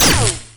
sound / weapons / taser.ogg
1fbff5f83b23d39d38b1dfcb4cac8d9b 00c382e117 [MIRROR] Converts almost every single sound to mono 44.1khz, recuts some sounds.
taser.ogg